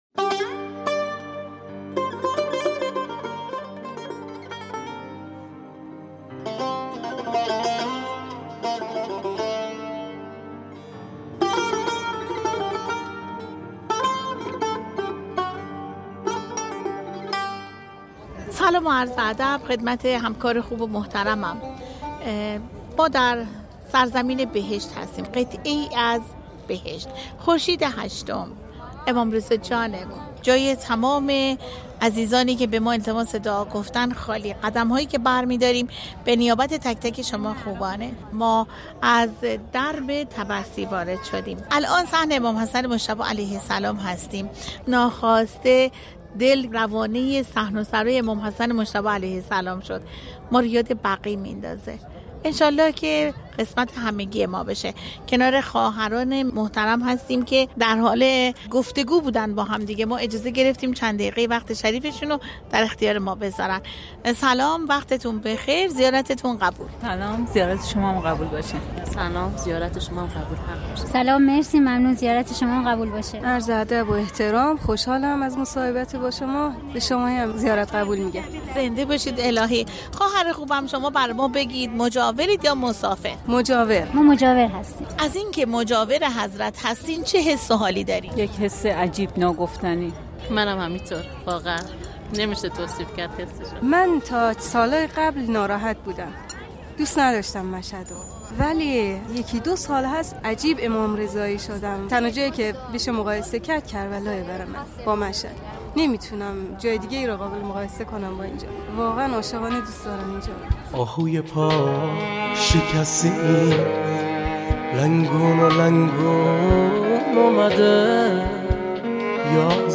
این سؤالات در گزارش خبرنگار رادیو رضوی با زائران پاسخ داده می‌شود.